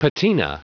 Prononciation audio / Fichier audio de PATINA en anglais
Prononciation du mot : patina